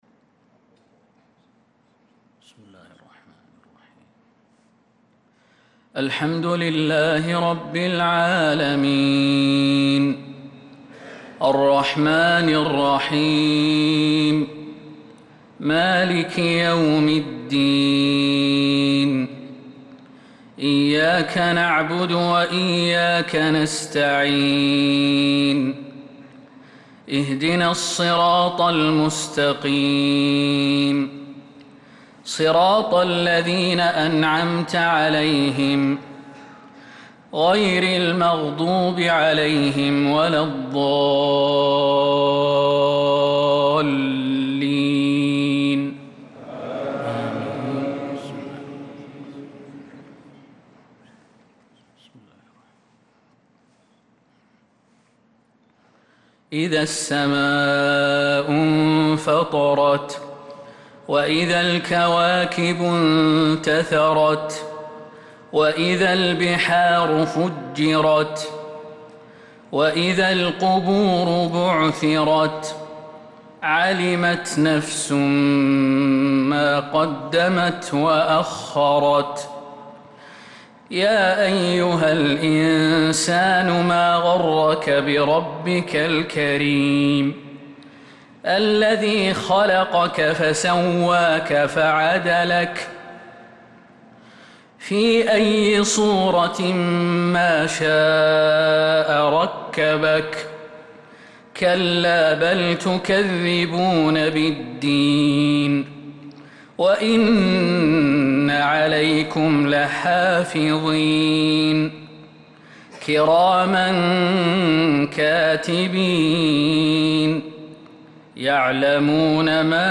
صلاة المغرب للقارئ خالد المهنا 15 ذو القعدة 1445 هـ